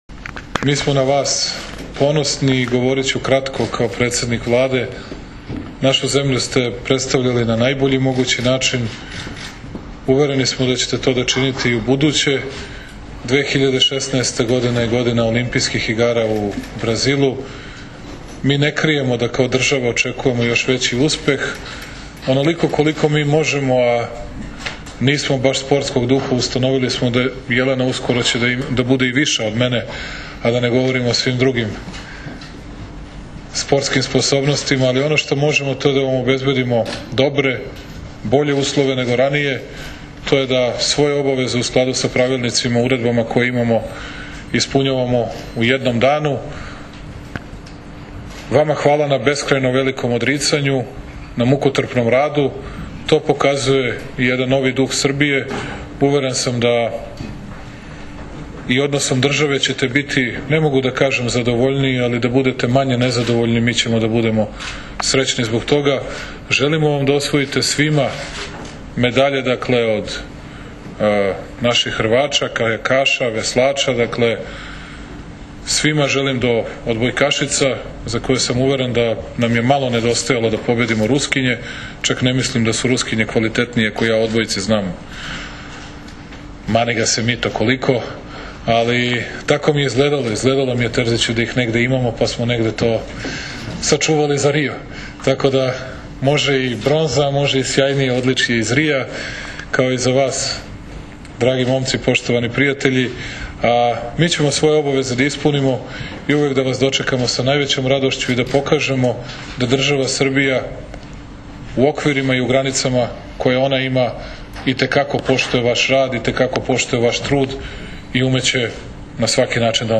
Predsednik Vlade Srbije priredio prijem za bronzane odbojkašice
IZJAVA ALEKSANDRA VUČIĆA